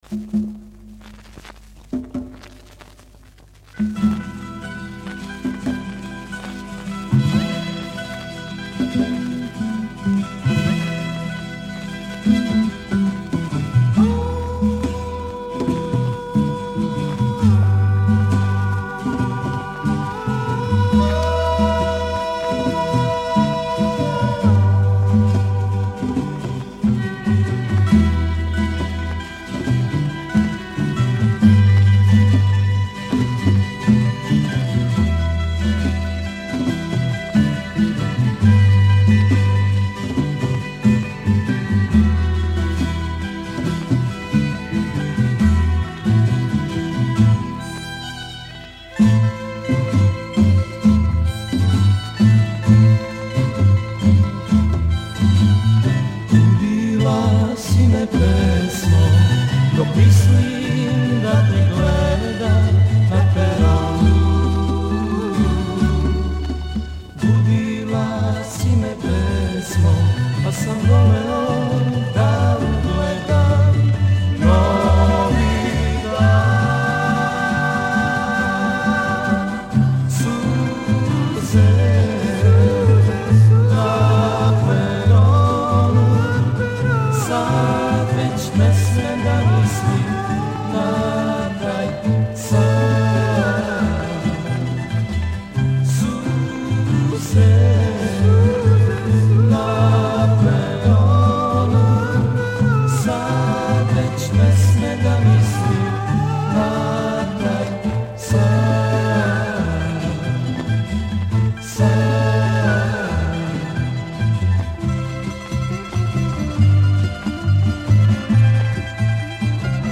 Genre:Rock, Pop, Folk, World, & Country
Style:Rock, Ballad